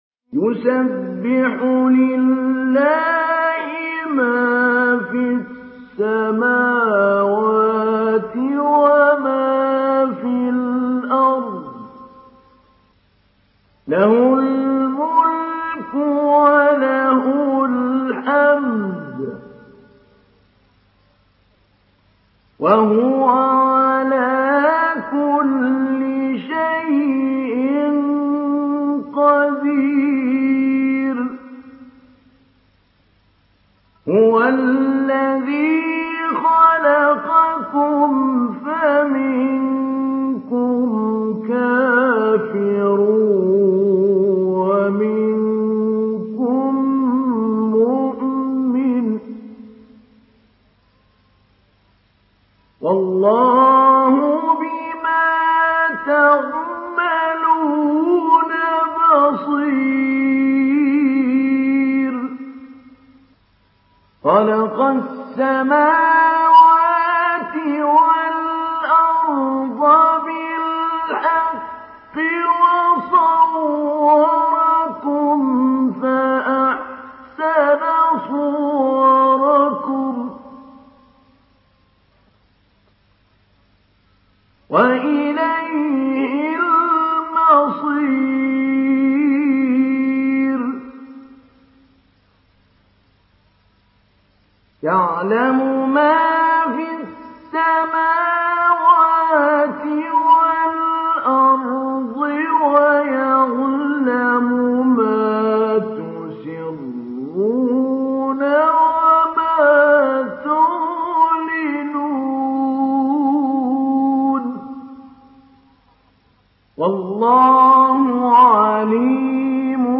Surah Tegabun MP3 in the Voice of Mahmoud Ali Albanna Mujawwad in Hafs Narration
Surah Tegabun MP3 by Mahmoud Ali Albanna Mujawwad in Hafs An Asim narration.